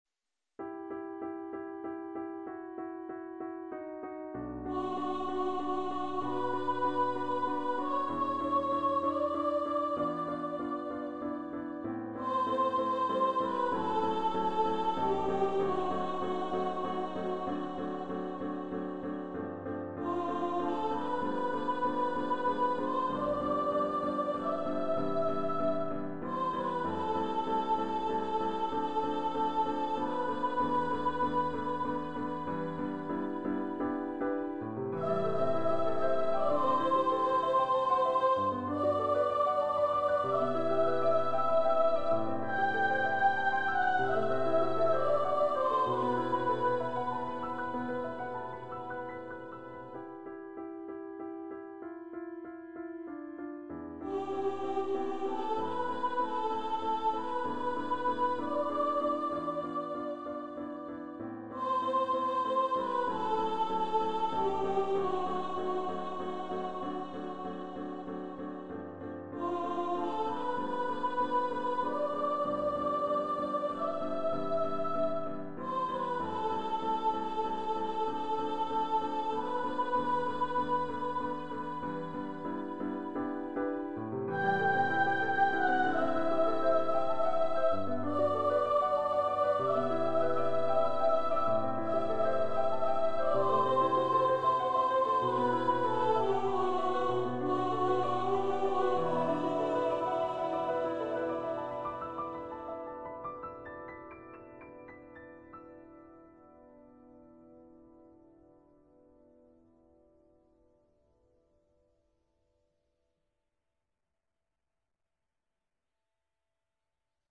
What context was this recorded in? Composer's Demo